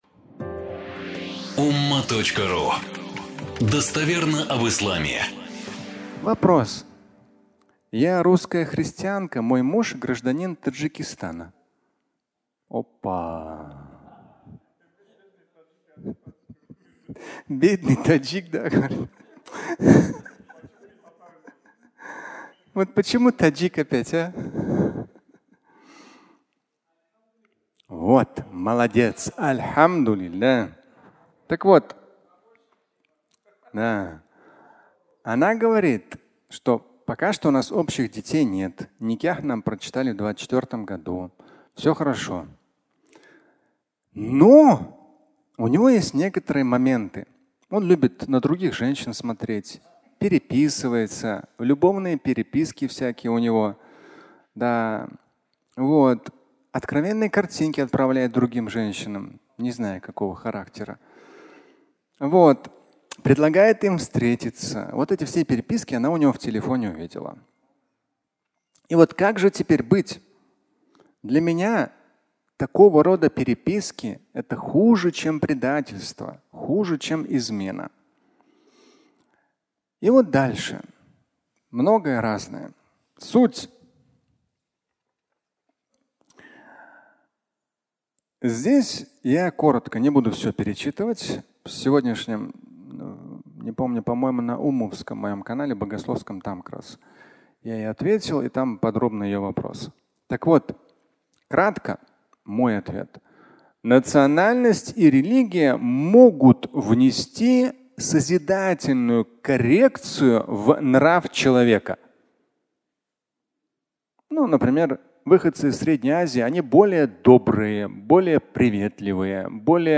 Фрагмент пятничной лекции
Пятничная проповедь